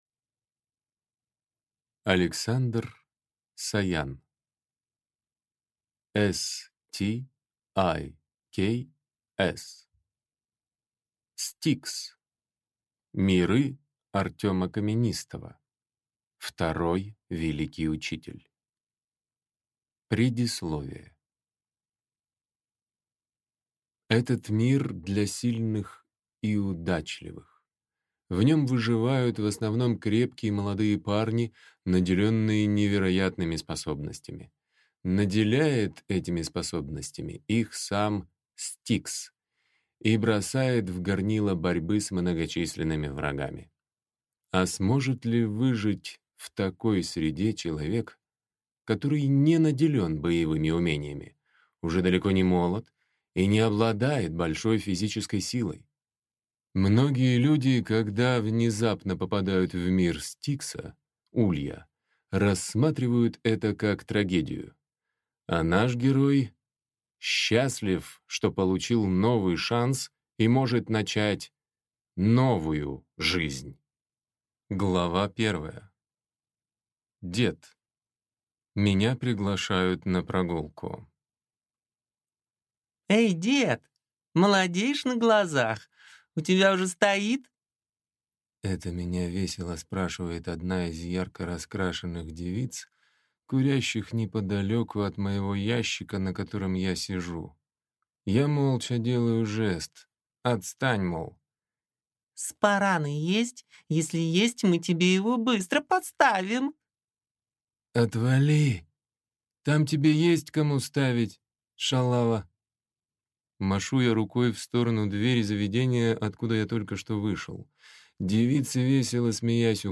Аудиокнига S-T-I-K-S Миры Артёма Каменистого. Второй Великий Учитель | Библиотека аудиокниг